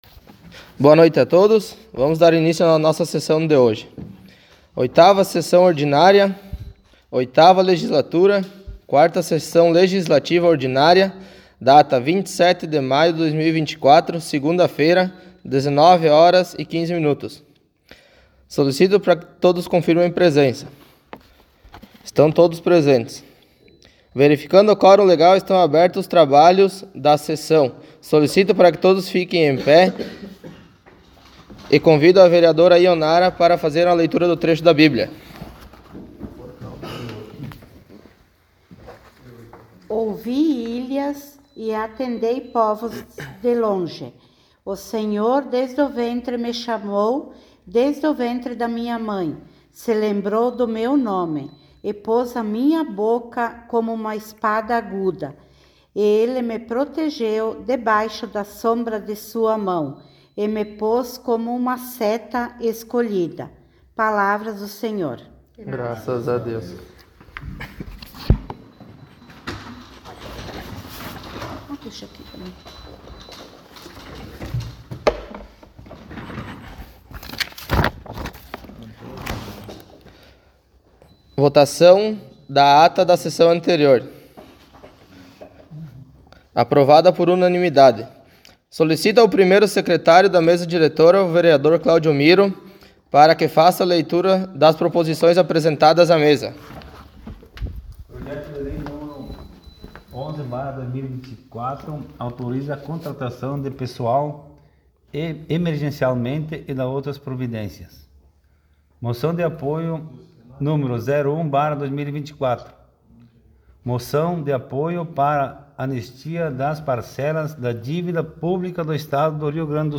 Audio da 8ª Sessão Ordinária 27.05.24